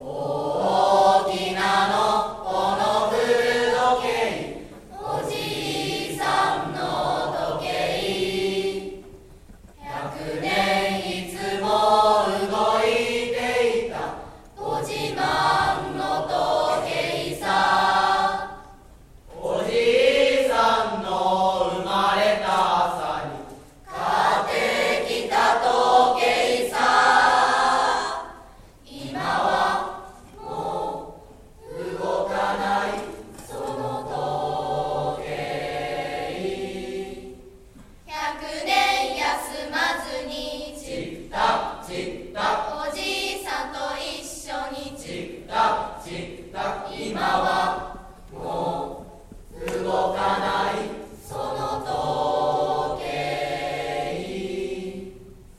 文化祭でのクラス合唱【音量に注意してください！】 3年2組「大きな古時計」